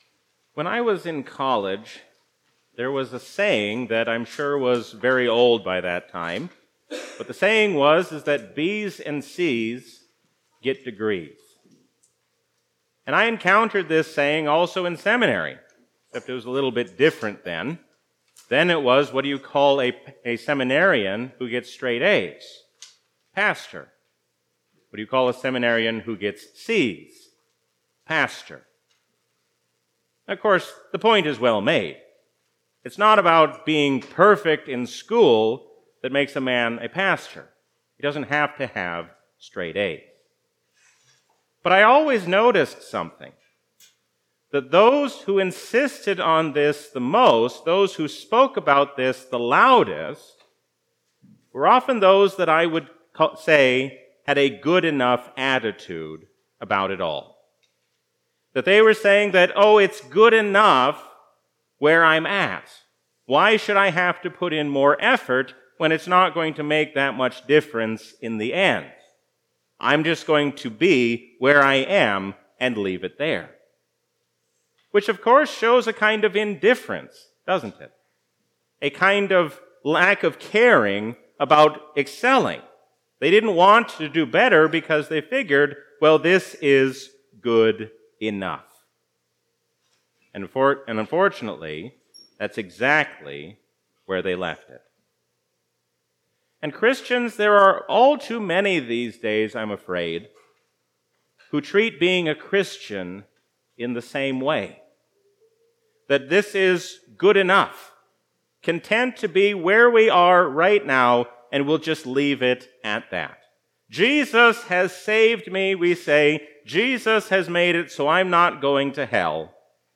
A sermon from the season "Lent 2022." Let us love, think, and act as one, because we are one in Christ.